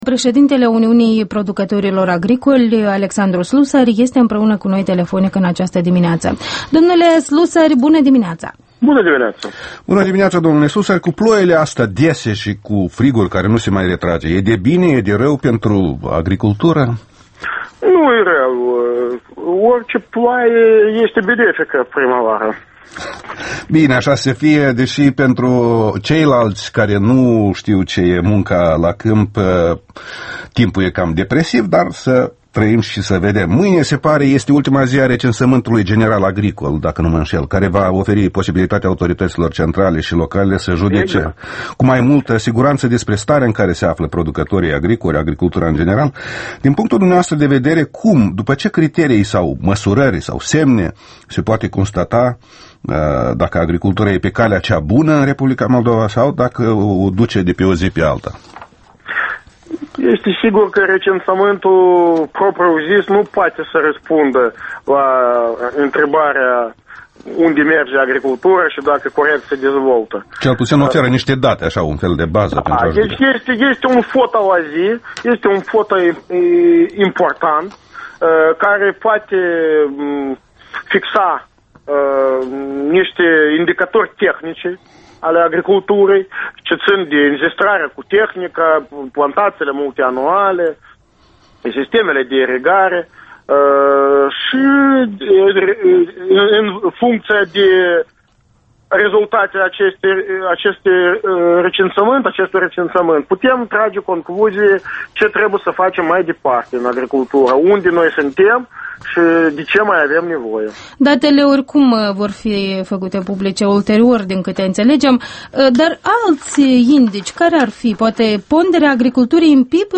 Interviul matinal EL: cu Alexandru Slusari despre subvenționări și situația forței de muncă